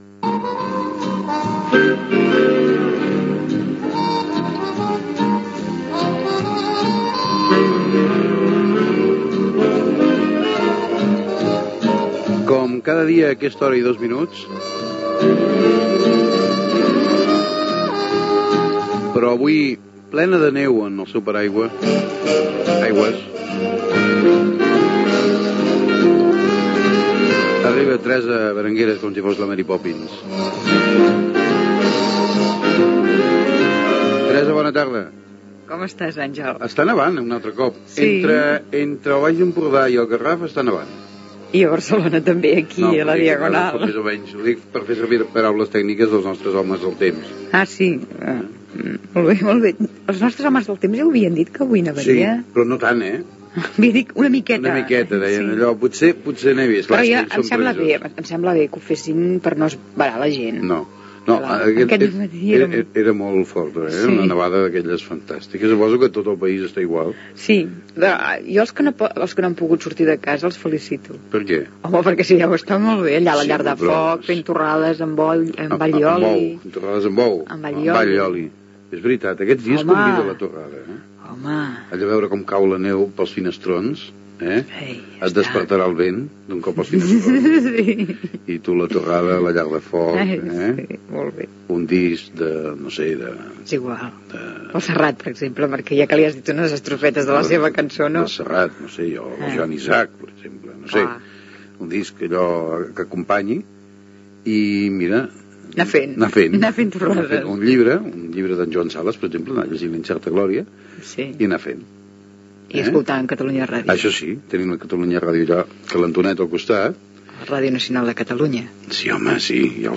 Sintonia, presentació, comentari sobre que neva a Barcelona, informació sobre Diane Keaton i la pel·lícula "Radio days " de Woody Allen
Entreteniment